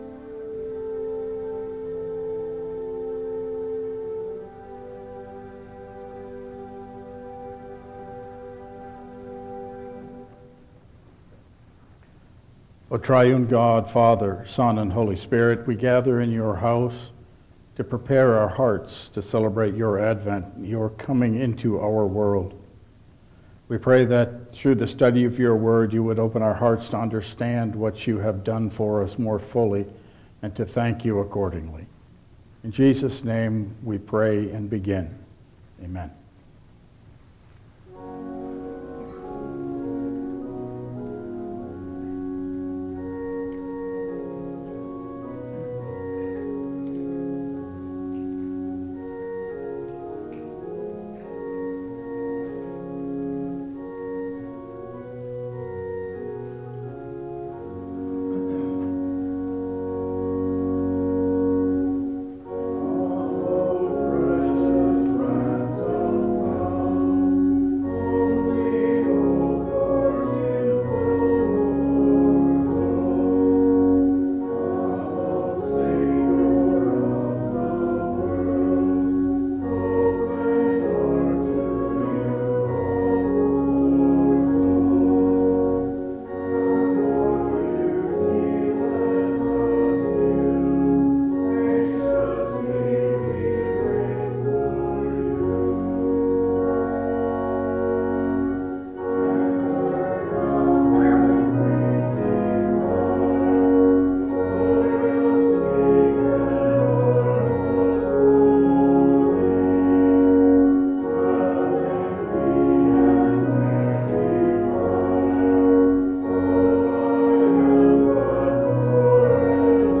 Download Files Printed Sermon and Bulletin
Service Type: Midweek Advent Service